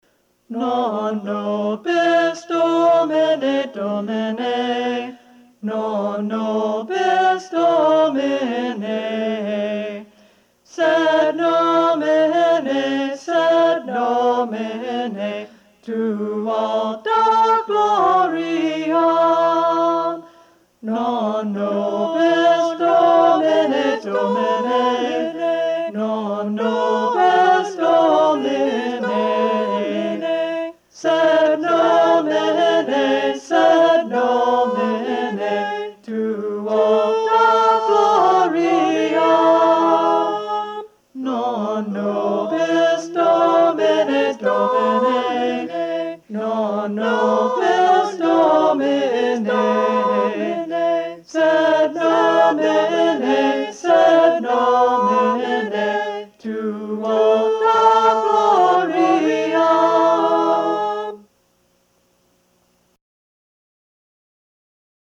(parts sung separately)